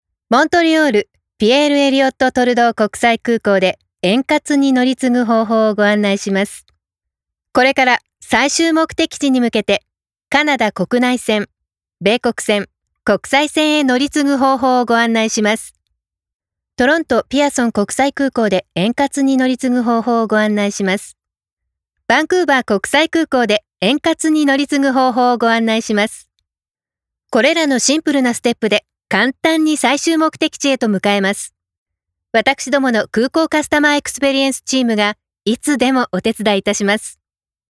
Female
Adult (30-50)
No dialect. Warm, sincere, diverse, emotive, dramatic, conversational, newscaster, presentation, mezzo-soprano, pleasant, expressive, educational, informative.
Live Announcer
1014Air_Canada_In_Flight_Announce.mp3